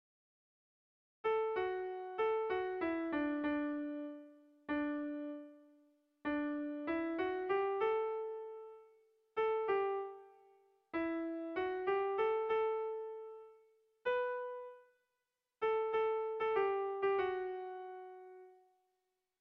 Gabonetakoa
AB